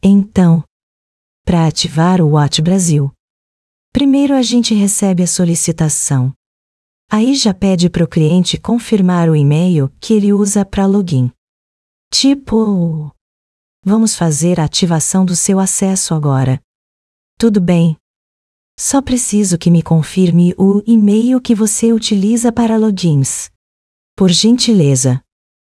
Áudios Gerados - Genesis TTS